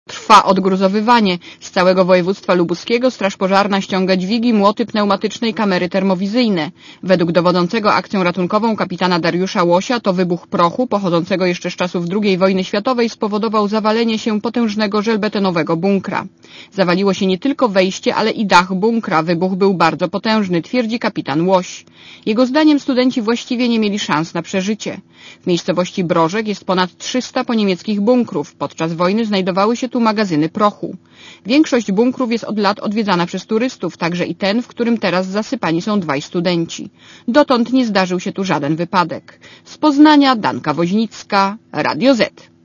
Mężczyźni wybrali się do Brożka koło Żar w województwie lubuskim, mieli tylko zwiedzać bunkry. 15.05.2003 | aktual.: 15.05.2003 16:58 ZAPISZ UDOSTĘPNIJ SKOMENTUJ © (RadioZet) Komentarz audio (170Kb)